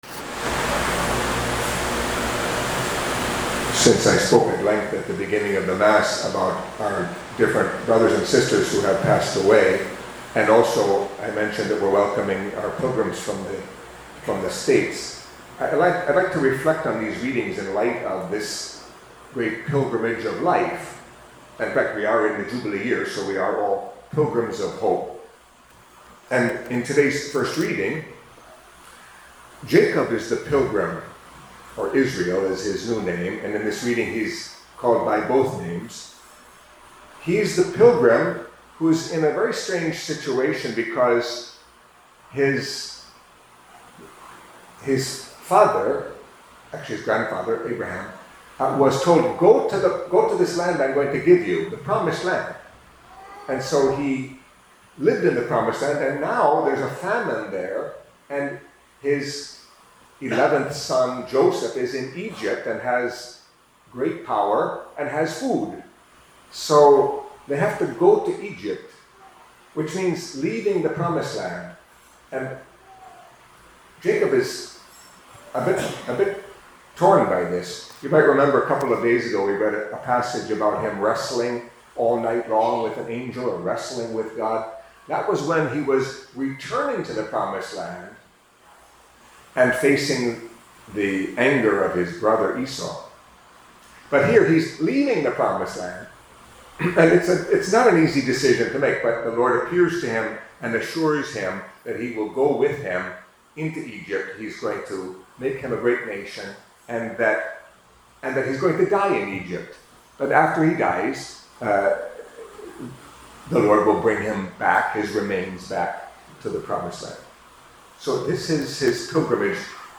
Catholic Mass homily for Friday of the Fourteenth Week in Ordinary Time